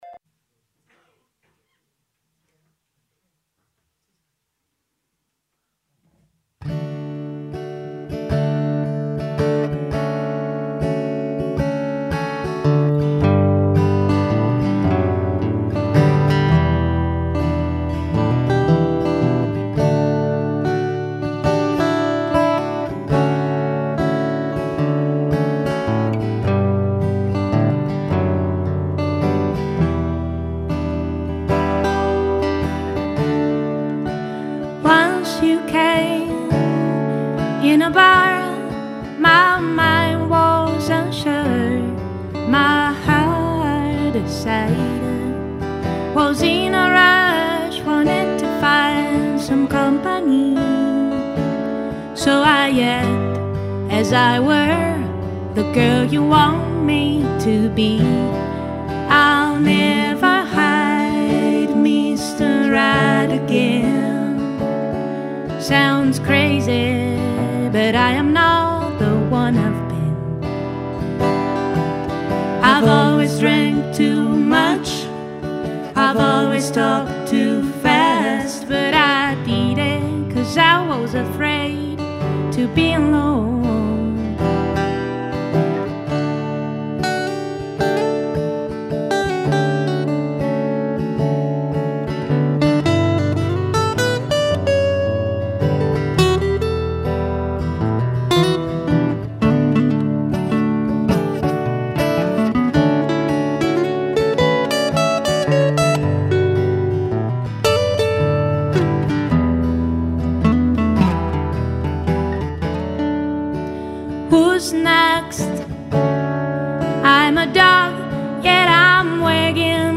Il giornalista, autore televisivo e scrittore Alessandro Robecchi, di cui è appena uscito “Pesci Piccoli”, il suo ultimo romanzo edito da Sellerio, torna a Radio Popolare per un reading che avrà al centro la sua Milano e quella di Monterossi. Pagine estrapolate dai suoi romanzi che raccontano la metropoli meneghina.